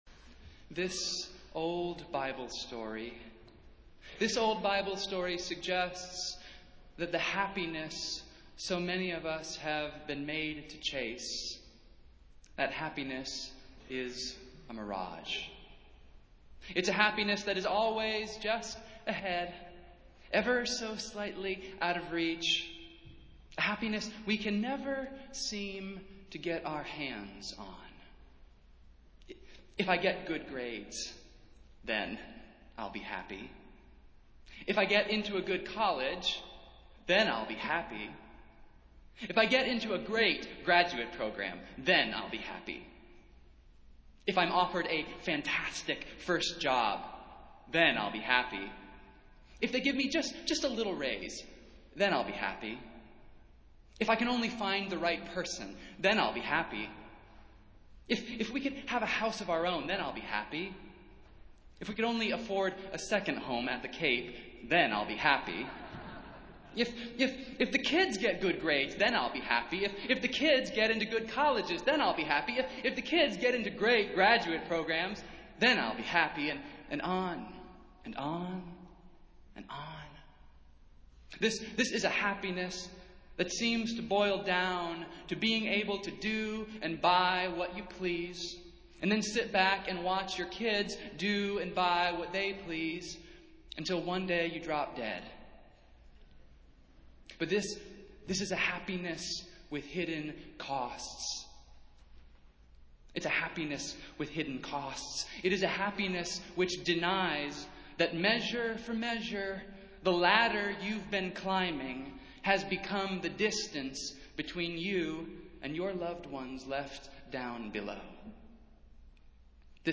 Festival Worship - First Sunday in Lent